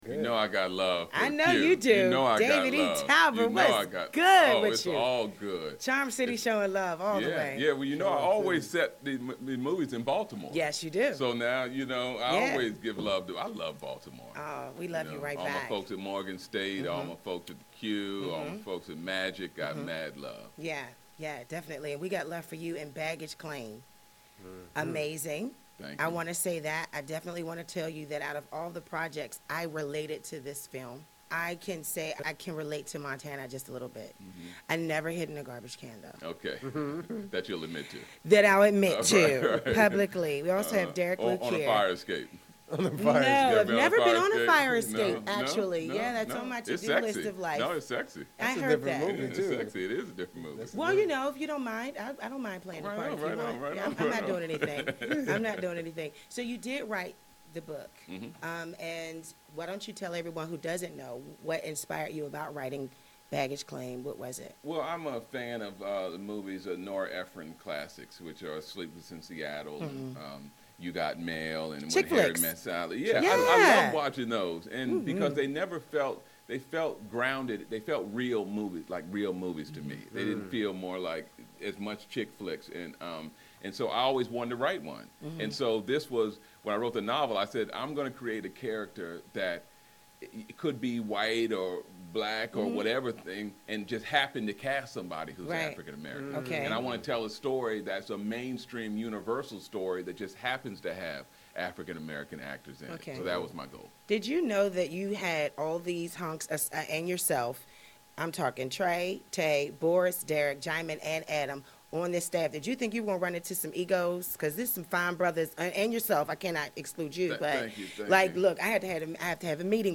two-on-one-derek-luke-and-david-e-talbert.mp3